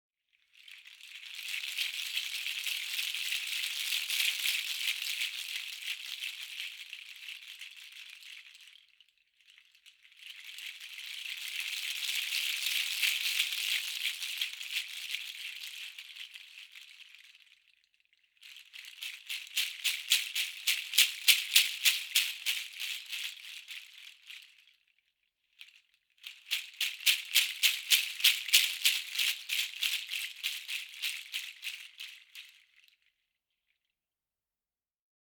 Bendo seed nutshells, attached to a wrist strap, create a unique, versatile instrument.